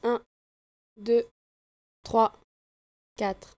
Fichier Audio 4 canaux pour tester: